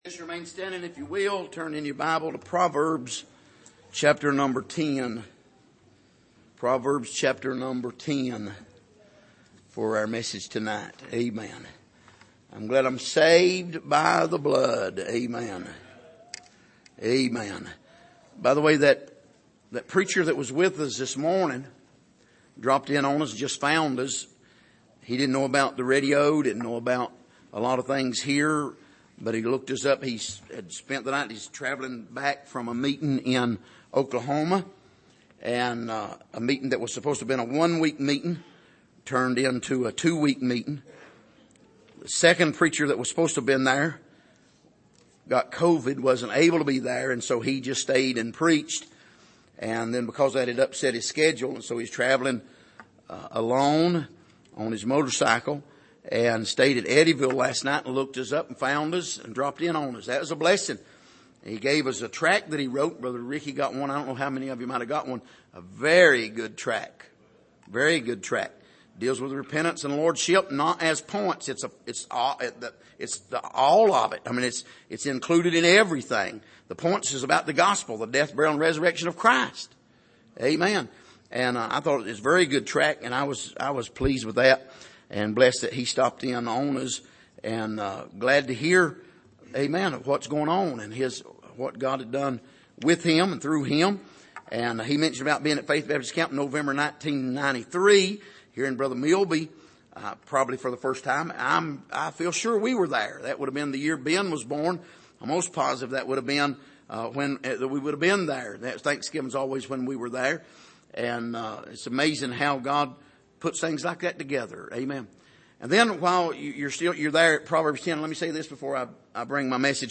Passage: Proverbs 10:13-17 Service: Sunday Evening